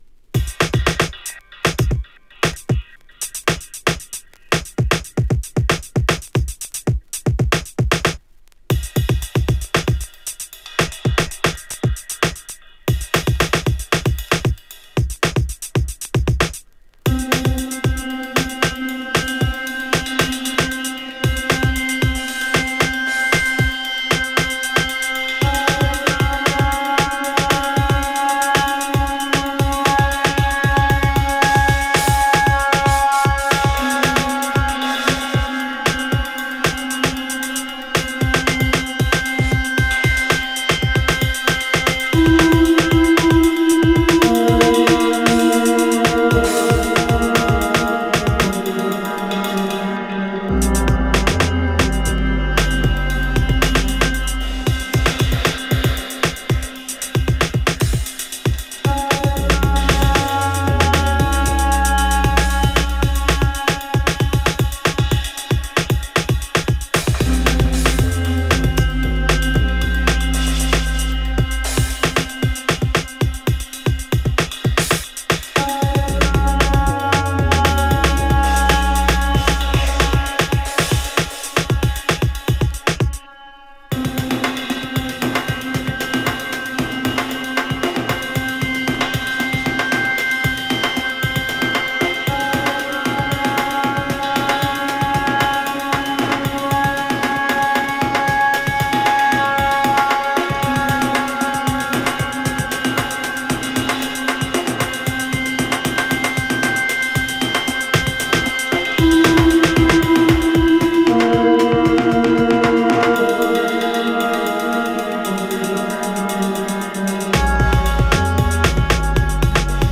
> BASS / DUB STEP / DRUM N' BASS